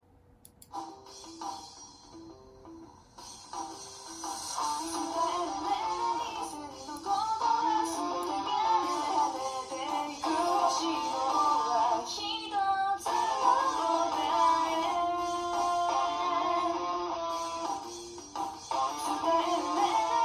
正確な音質の判断にはなりませんが、PCディスプレイのスピーカーとの違いは十分伝わると思います。
【曲2】PCディスプレイ内蔵スピーカー
フリー音楽